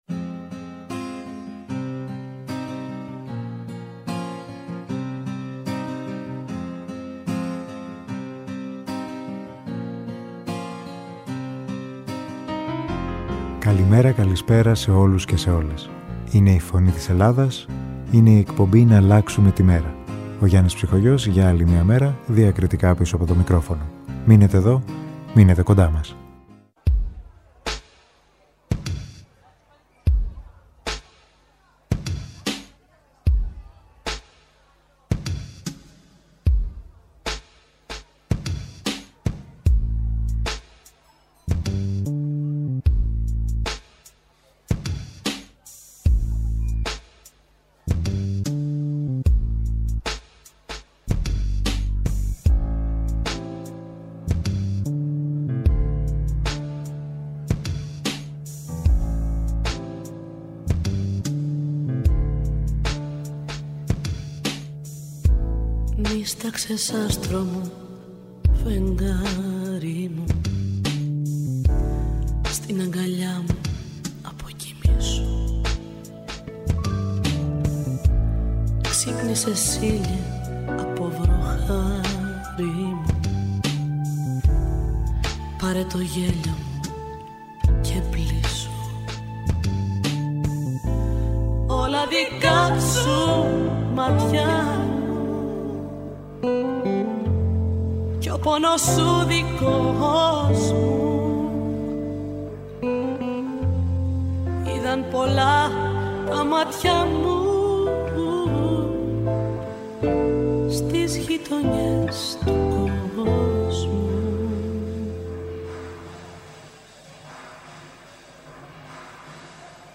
Μουσική